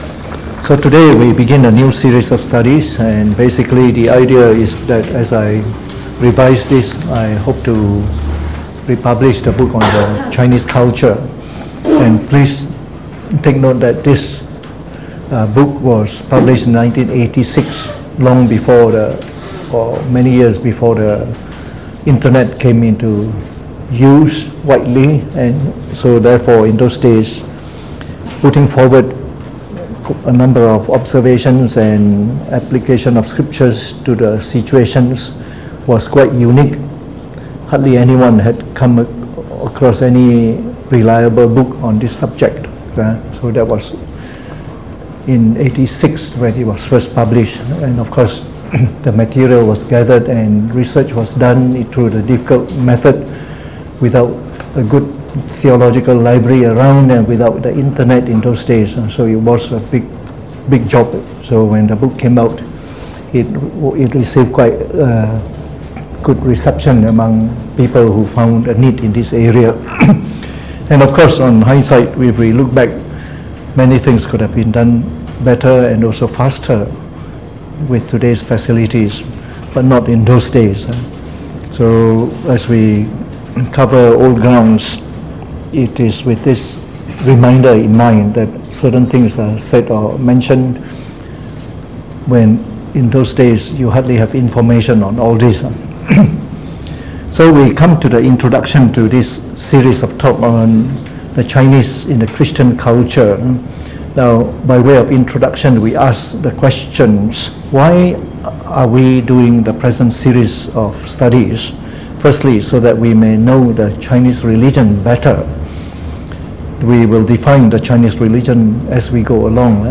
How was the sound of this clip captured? Delivered on the 7th of August 2019 during the Bible Study, from the new series on The Chinese Religion?